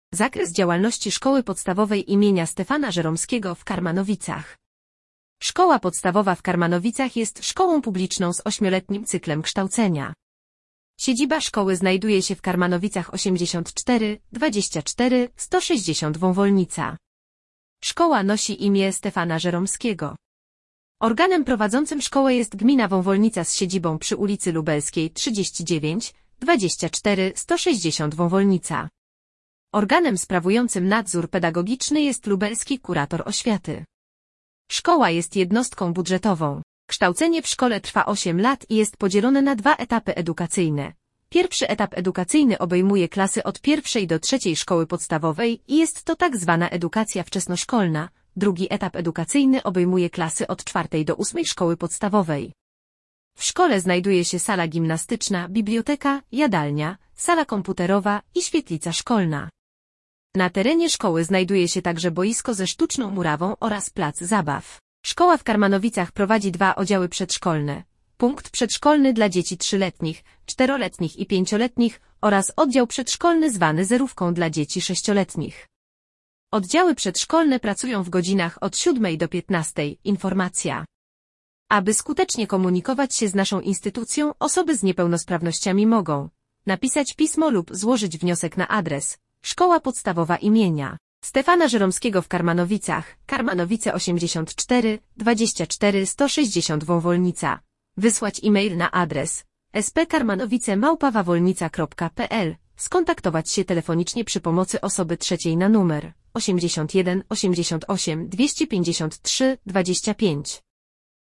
Informacja do odsłuchania odczytywana przez lektora